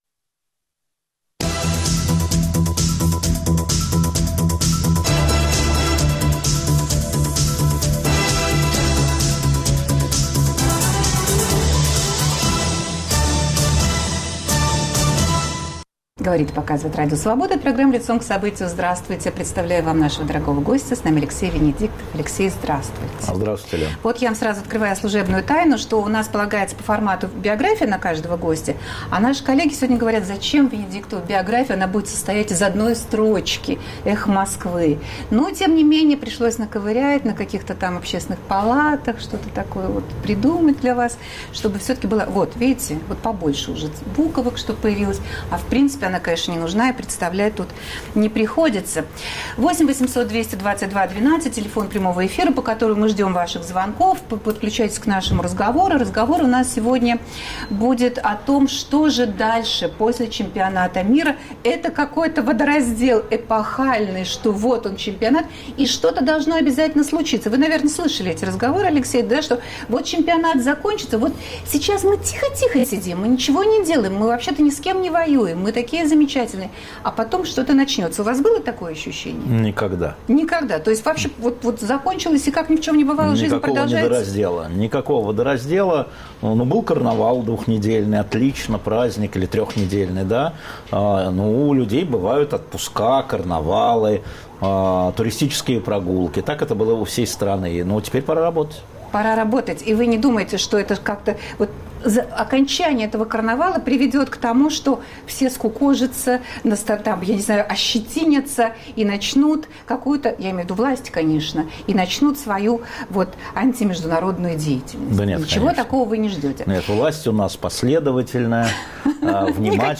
Что ждет Россию и россиян после футбольного карнавала? Гость студии - главный редактор радиостанции "Эхо Москвы" Алексей Венедиктов.